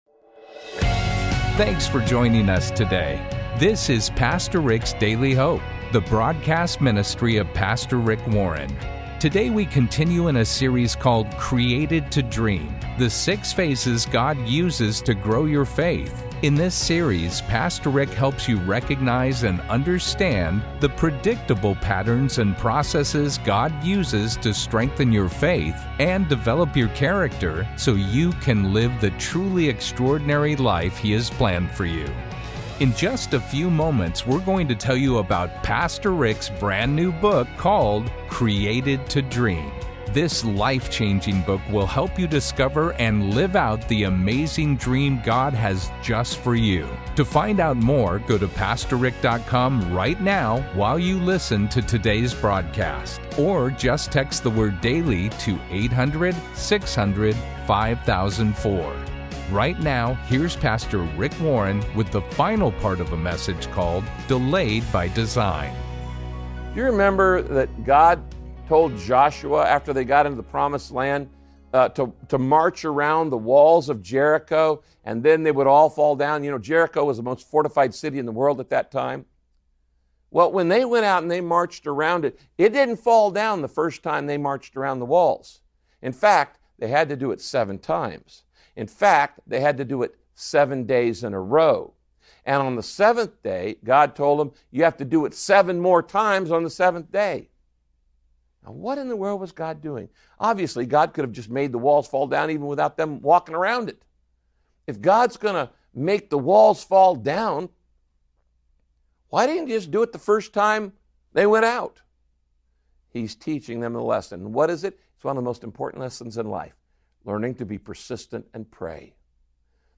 When that happens, you have two choices: You can try to figure it out on your own, or you can have faith in God’s timing. In this message, Pastor Rick shares how to trust God’s timing when you are delayed.